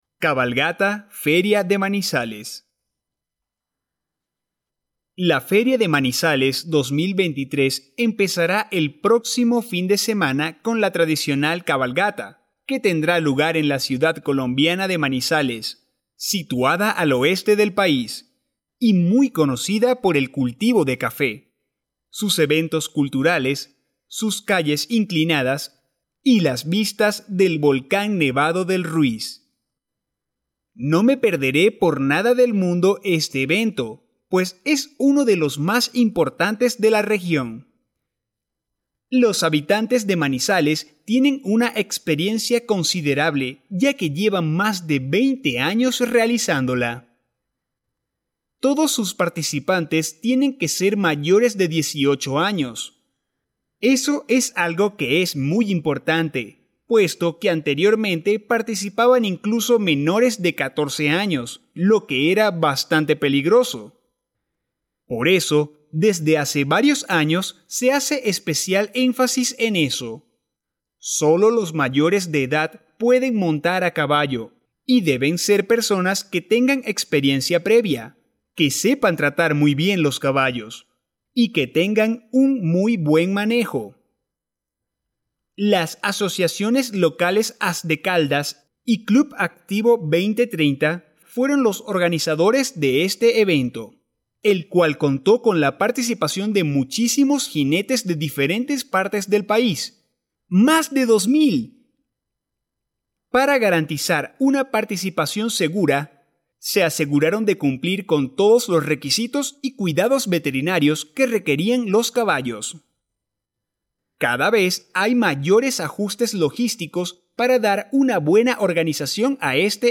Spanish online reading and listening practice – level B2